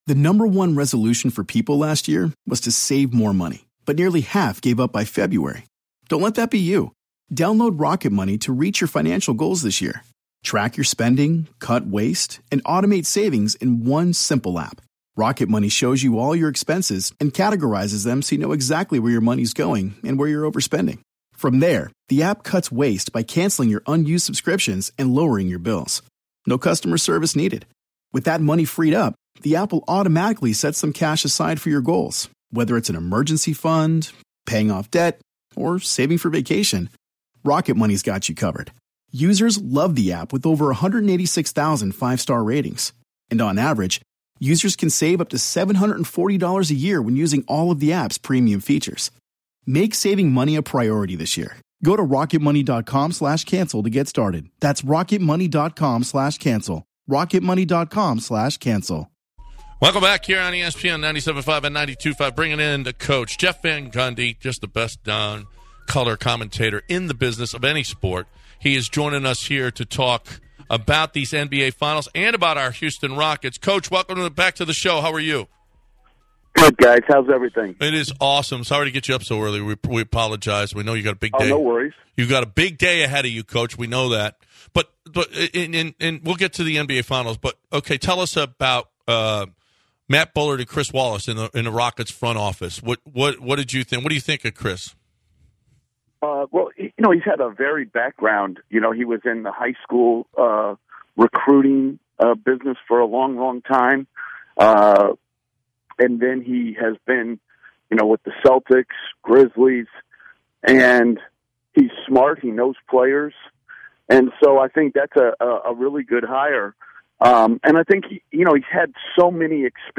Jeff Van Gundy, ESPN NBA broadcaster, joins The Bench to discuss the Rockets' draft pick among other topics dealing with the NBA Finals.